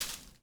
SFX_paso6.wav